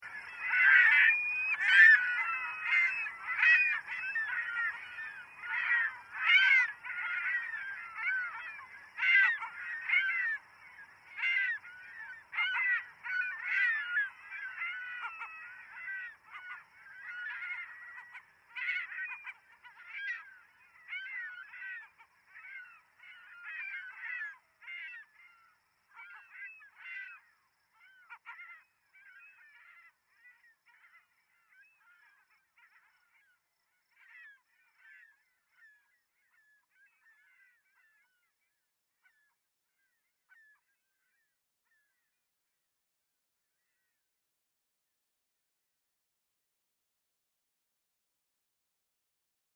Scripted Ambient Sounds - Seagulls
Even at max volume, the birds are barely audible. =(
seagulls_01_DEMO